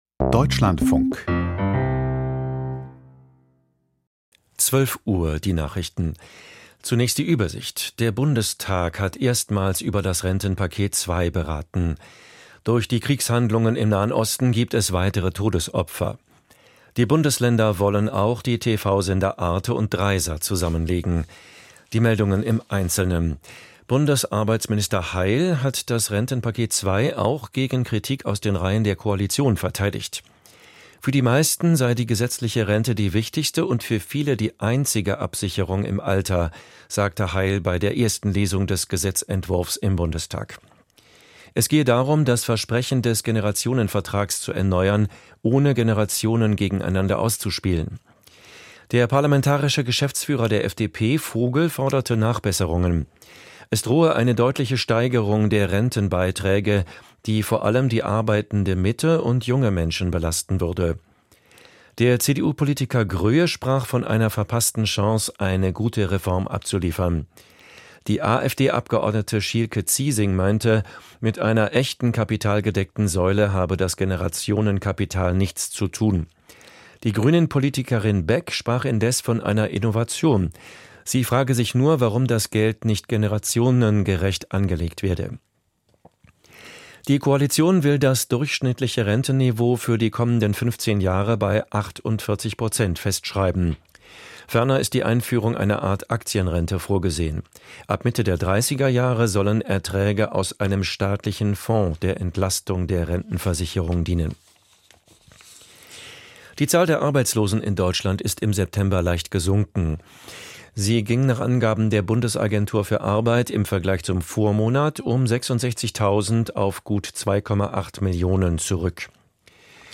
Bremsen die USA ihre Unterstützung für die Ukraine? - Interview